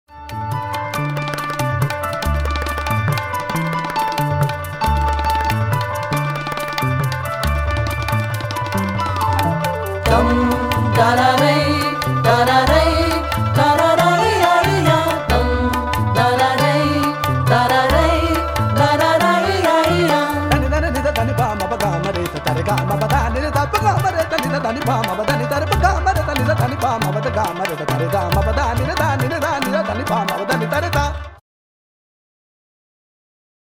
Recorded at Lahore Folk Studio Dec '05